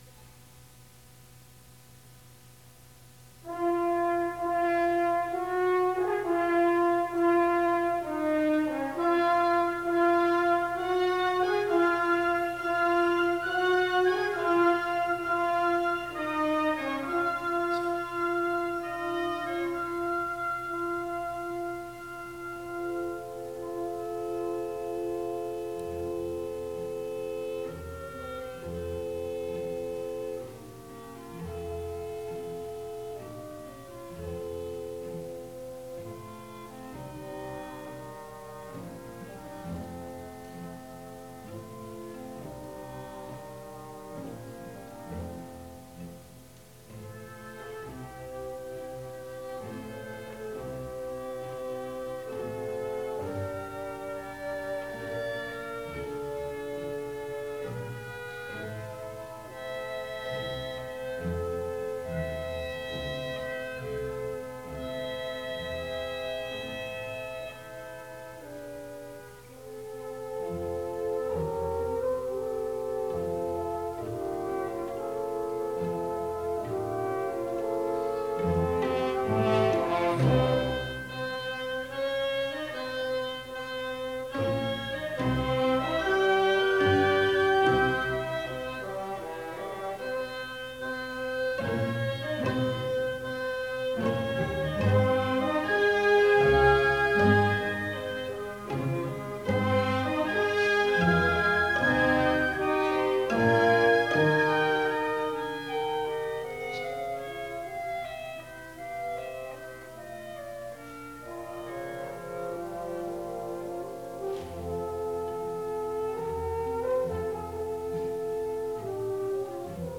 第29回定期演奏会 神戸文化ホール